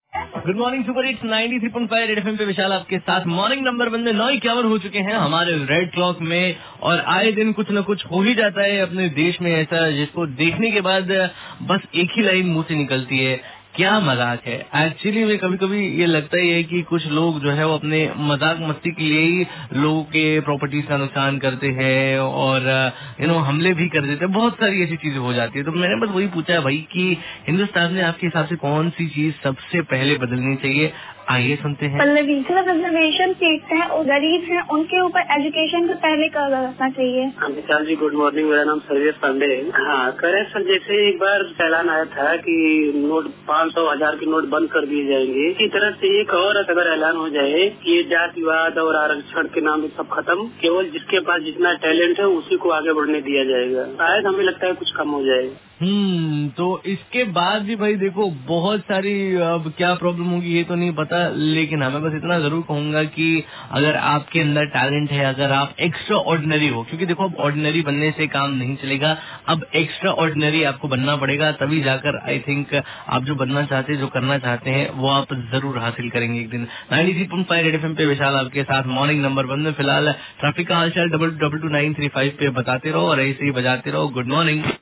CALLER INTERACTION